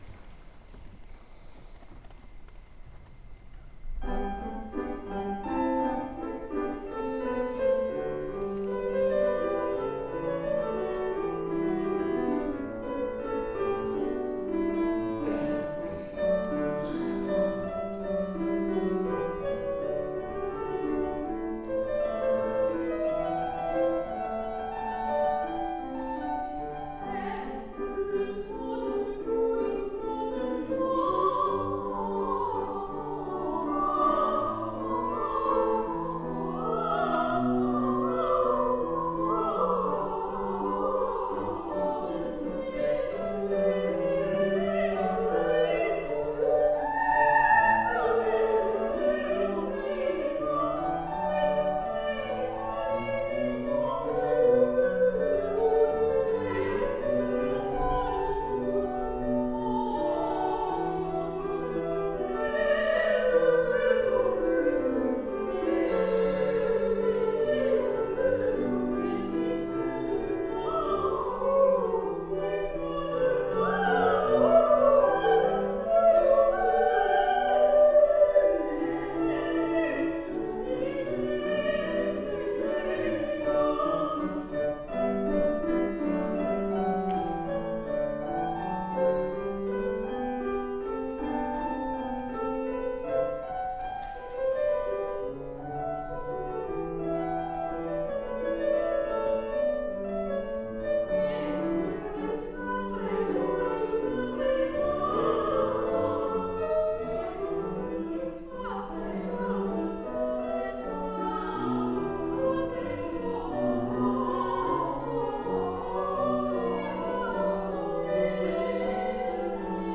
Performance in 2017
August 12, 2017, International Summer Academy of Music, Nice, France